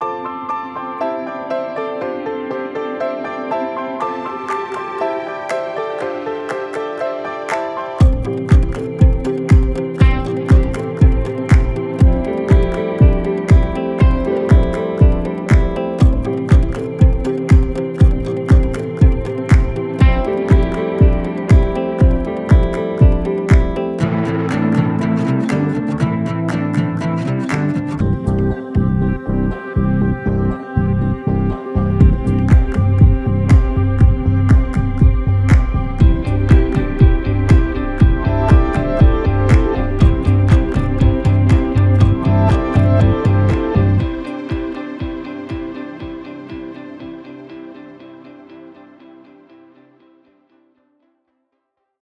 pop song